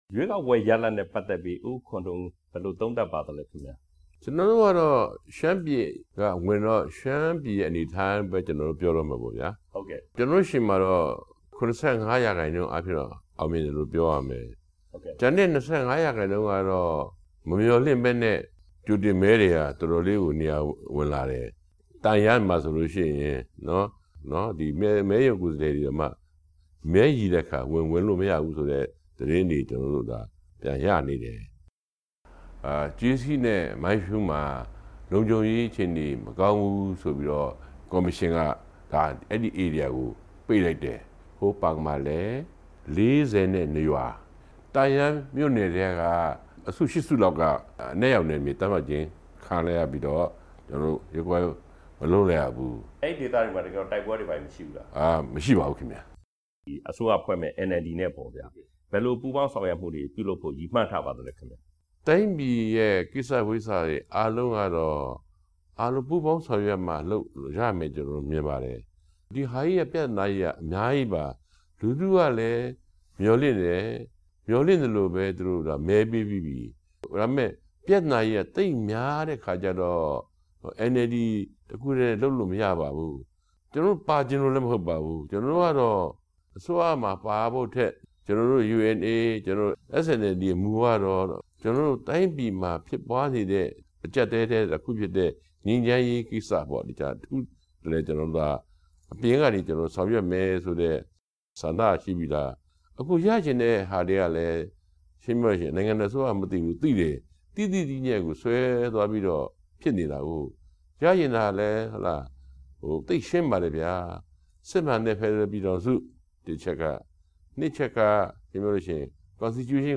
SNLD ဥက္ကဌ ဦးခွန်ထွန်းဦးနဲ့ မေးမြန်းချက် အပိုင်း (၁)